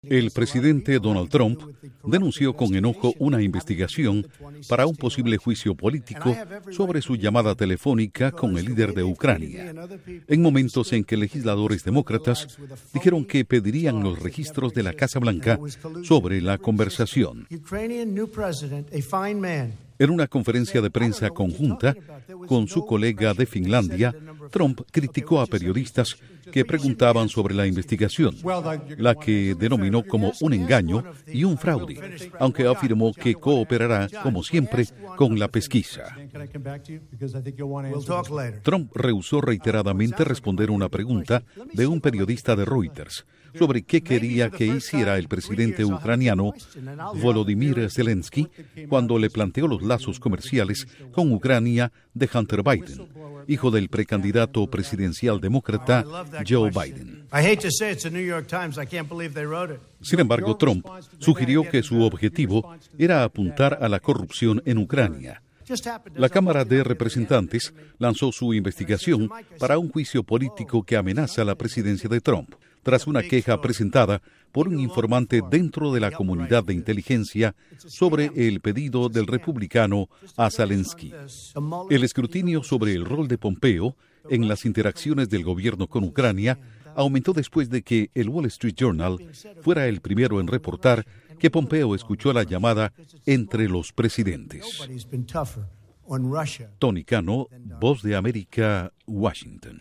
Duración: 1:39 Incluye declaraciones de Trump (Republicano) y de los Demócratas